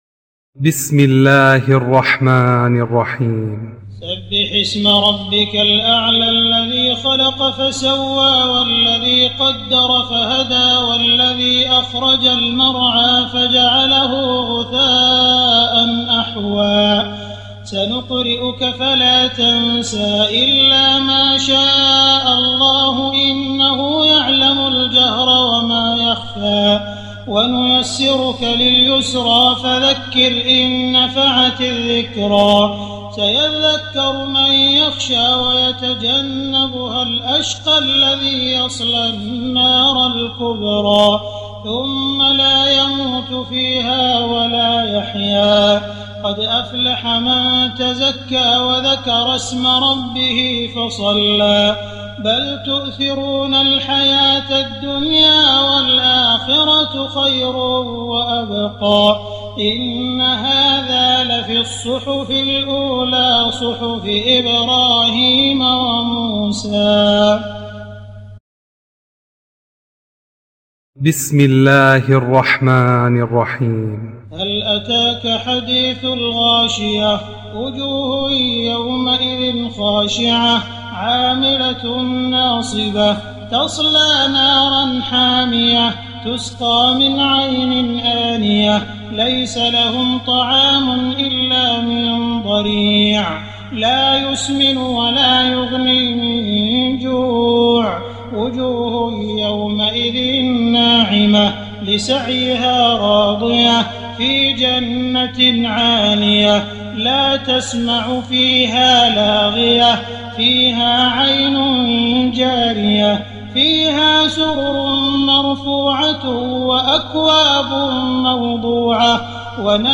تراويح ليلة 29 رمضان 1419هـ من سورة الأعلى الى الناس Taraweeh 29 st night Ramadan 1419H from Surah Al-A'laa to An-Naas > تراويح الحرم المكي عام 1419 🕋 > التراويح - تلاوات الحرمين